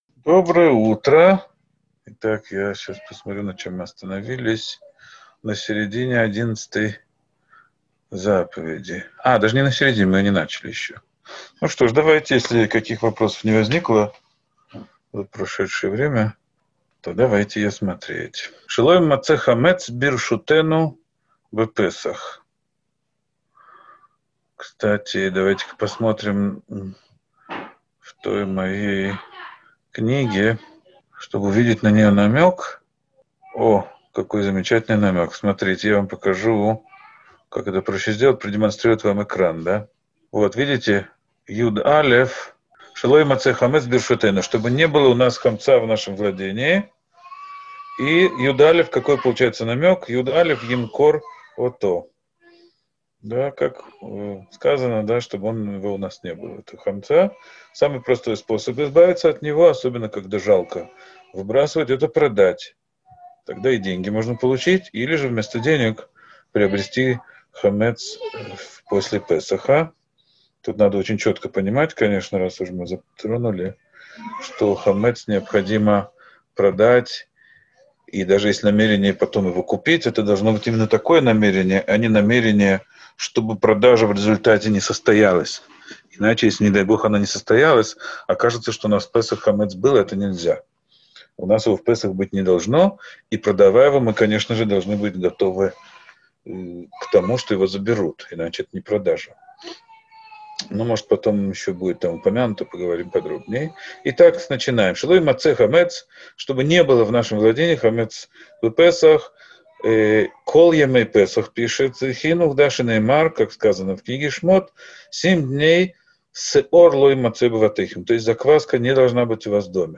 Урок 15.